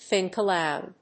thínk alóud